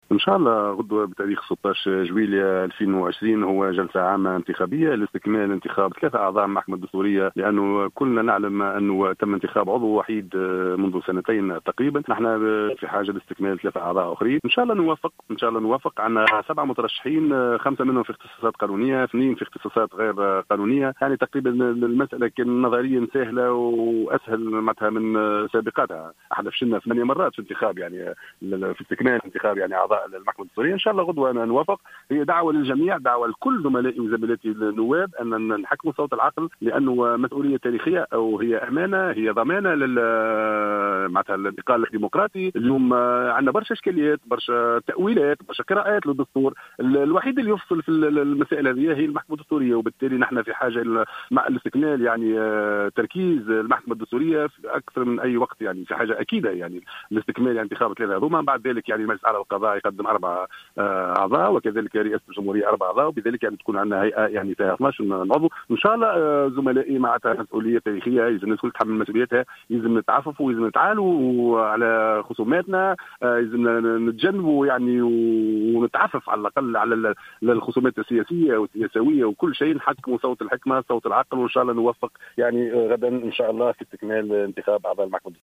أكد النائب طارق الفتيتي في تصريح للجوهرة "اف ام" انه سيتم غدا عقد جلسة عامة لإستكمال انتخاب 3 اعضاء للمحكمة الدستورية من بين 7 مترشحين.